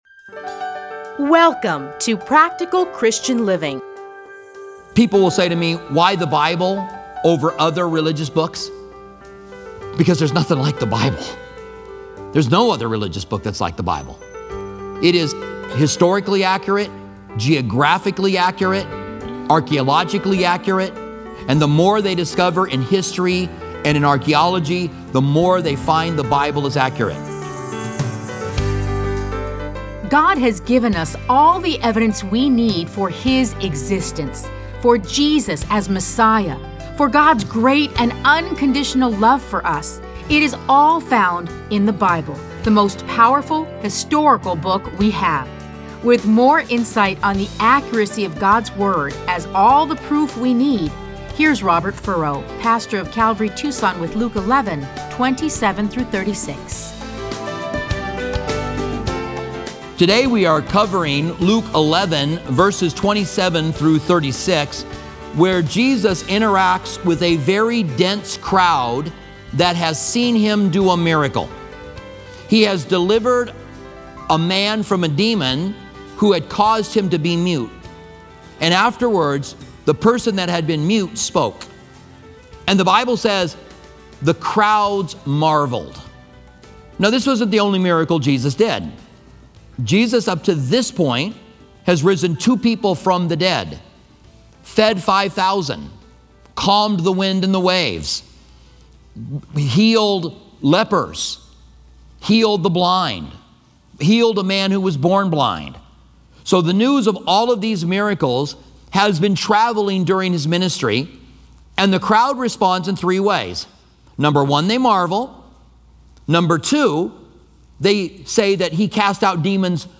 Listen to a teaching from Luke Luke 11:27-36.